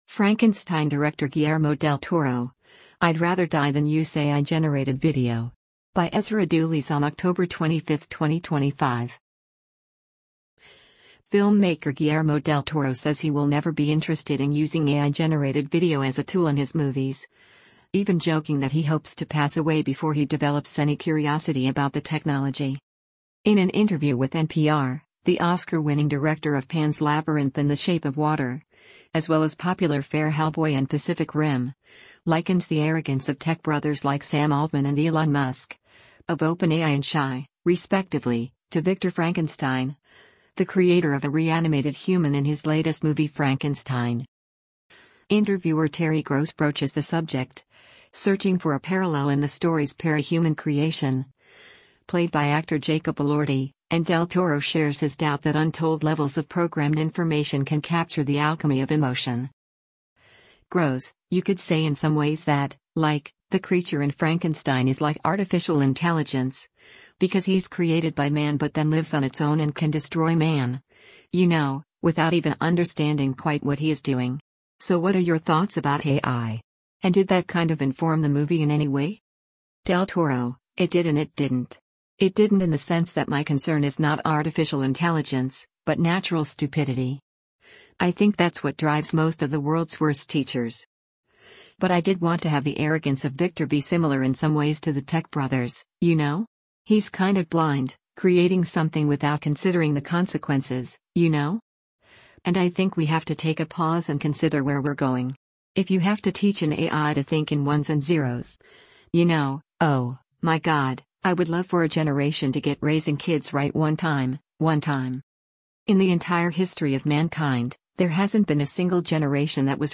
In an interview with NPR, the Oscar-winning director of Pan’s Labyrinth and The Shape of Water (as well as popular fare Hellboy and Pacific Rim) likens the “arrogance” of “tech bros” like Sam Altman and Elon Musk (of OpenAI and xAI, respectively) to Victor Frankenstein, the creator of a reanimated human in his latest movie Frankenstein.